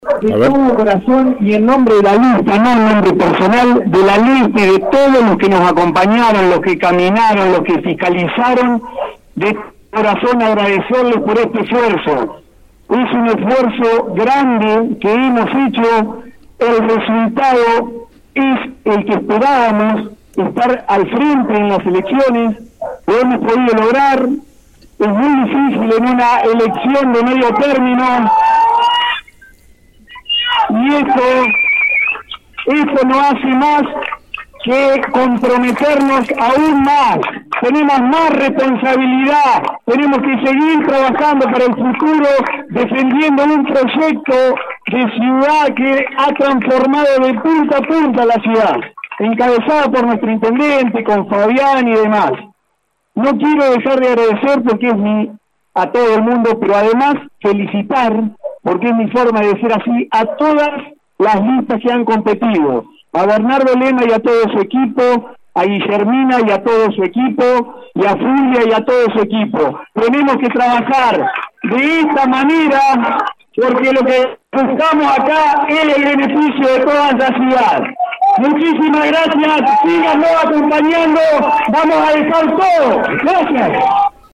(foto archivo) Conocidos los resultados de la elección legislativa del domingo, el electo concejal por el oficialismo dejó su mensaje a los votantes. Fue en el local de Todos por Las Flores (Avda. Rivadavia y Avda. San Martín) ante la presencia de un importante número de seguidores.